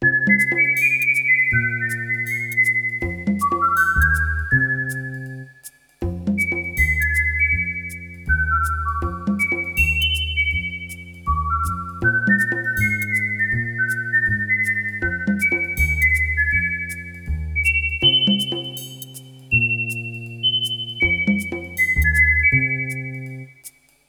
melodía
sintonía